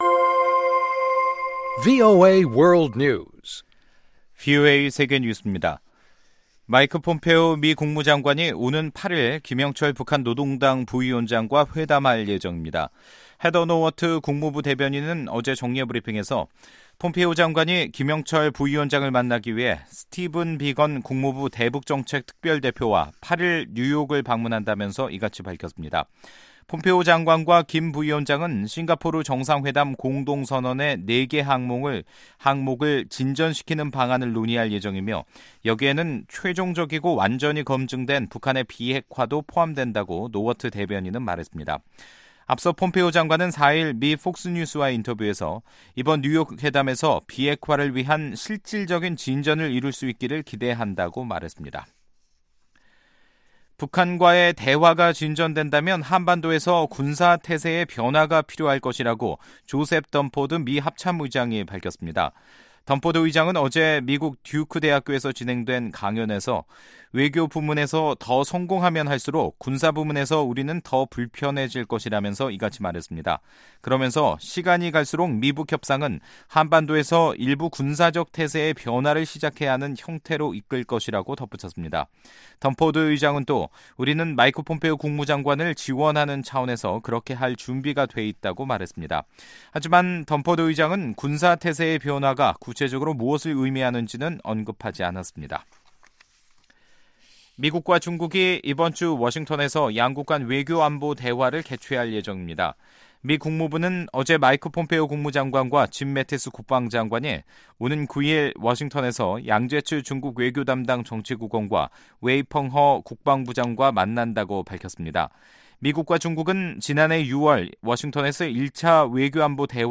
VOA 한국어 간판 뉴스 프로그램 '뉴스 투데이', 2018년 11월 6일 2부 방송입니다. 이 시간 현재 미국에서는 국민의 관심 속에 중간선거가 진행 중인 가운데, 결과가 어떻든 미국의 대북 정책에는 큰 변화가 없을 것이란 관측이 나오고 있습니다. 북한은 비핵화를 촉구하는 유엔총회 결의가 북한의 약속 이행만을 강조하고 있다며 반발했습니다.